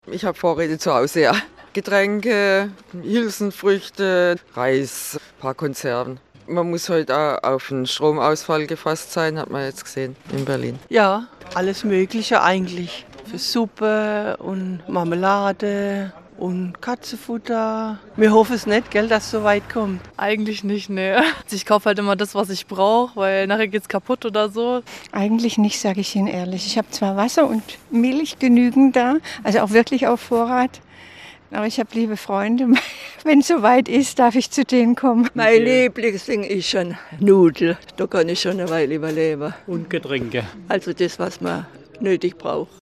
Der SWR hat in Bad Rappenau (Kreis Heilbronn) gefragt, welche Vorräte die Menschen dort privat so im Keller oder im Vorratsschrank haben, um für Krisen wie Stromausfälle, Hochwasser und Pandemien gerüstet zu sein.